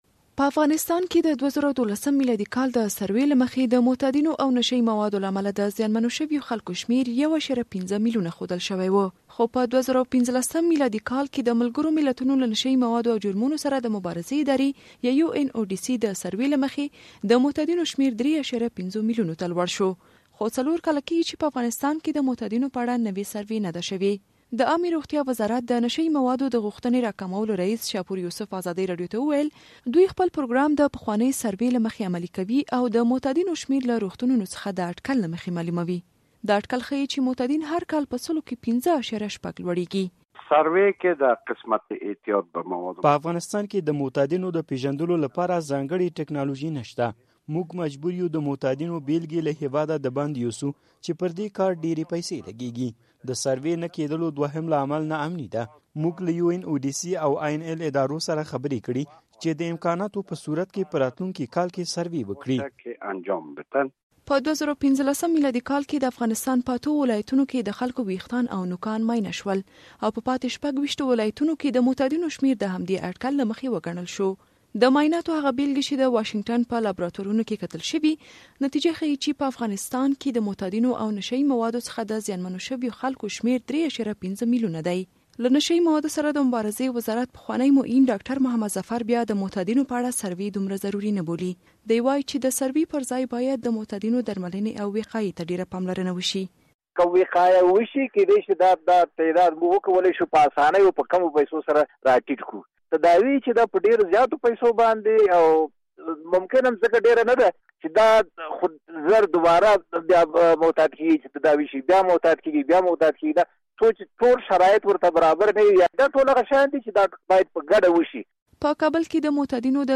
غږیز راپور